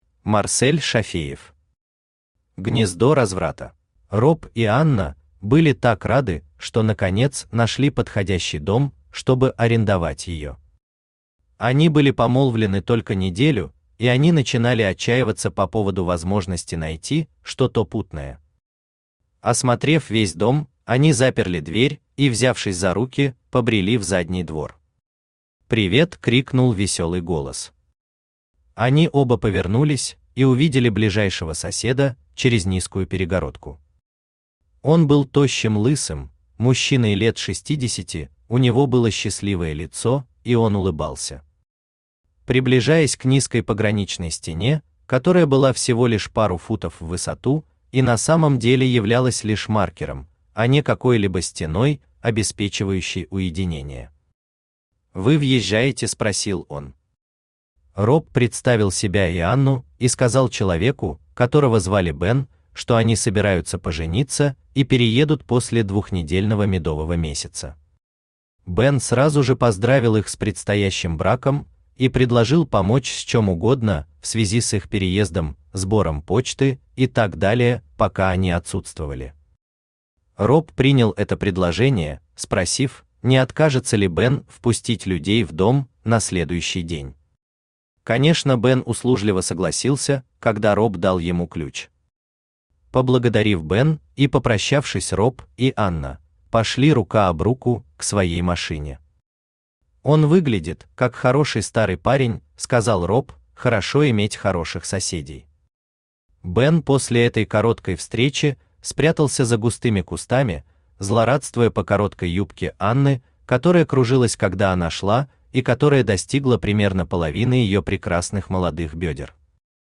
Аудиокнига Гнездо разврата | Библиотека аудиокниг
Aудиокнига Гнездо разврата Автор Марсель Зуфарович Шафеев Читает аудиокнигу Авточтец ЛитРес.